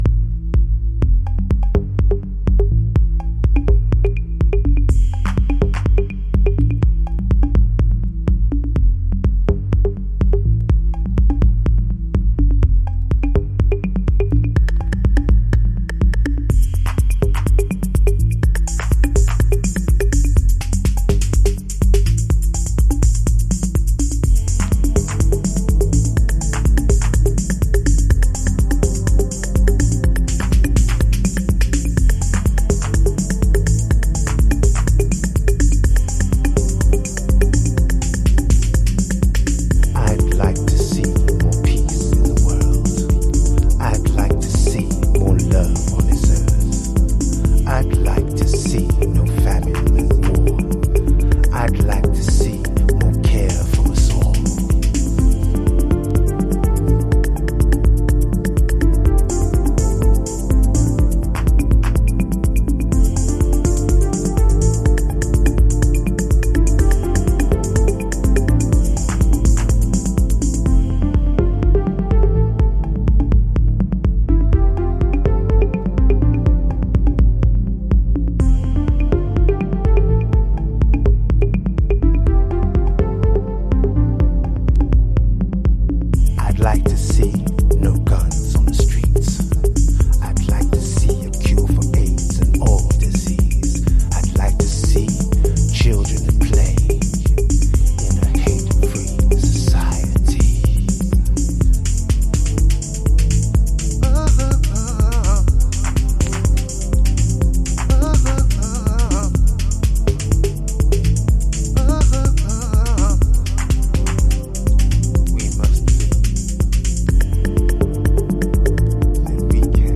Chicago Oldschool / CDH
Dub Mix